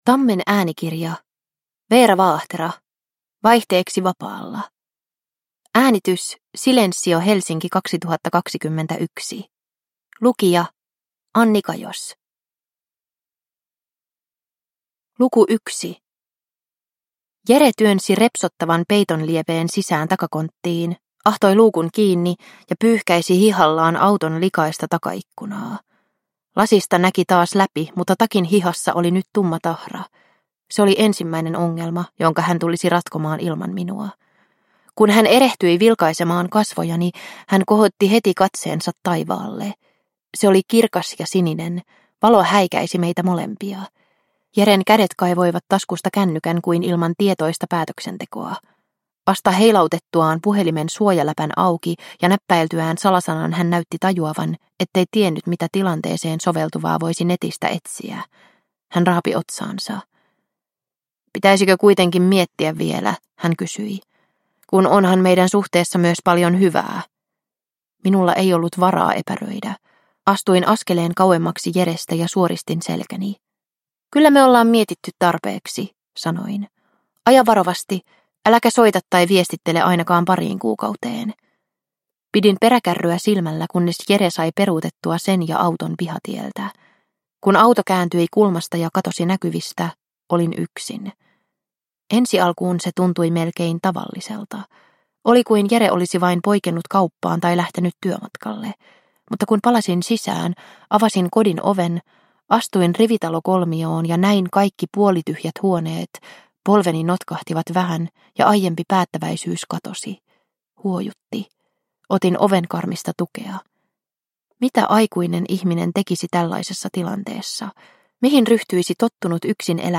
Vaihteeksi vapaalla – Ljudbok – Laddas ner